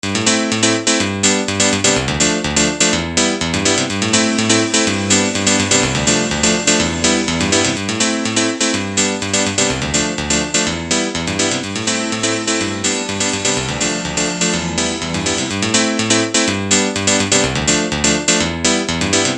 Hall
ukß╛ka halovania klavφra. Ako v╛dy je  prv² takt bez efektu, druh² s dlh²m ╣tadi≤novit²m hallom, tretφ je tesnej╣φ a ╣tvrt² je u╛ prehnane dlh². Posledn² pre porovnanie znova bez efektu.
hal_mix.mp3